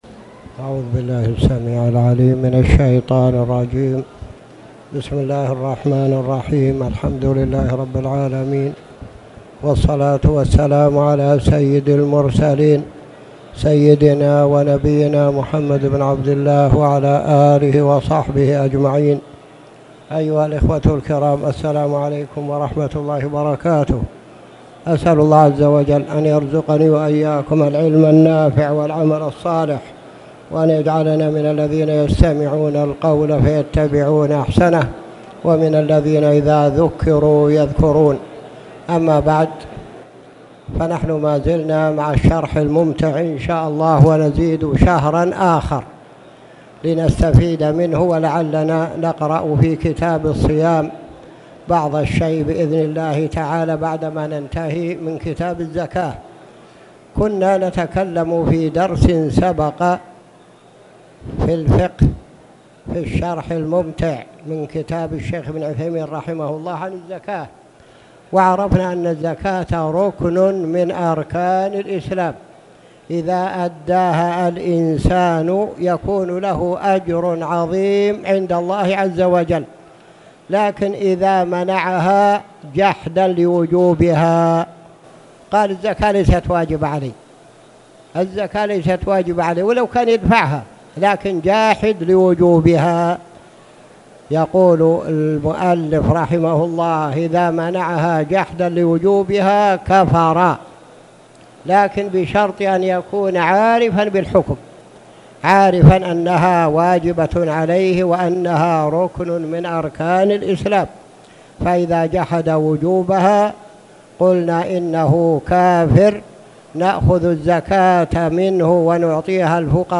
تاريخ النشر ٦ رجب ١٤٣٨ هـ المكان: المسجد الحرام الشيخ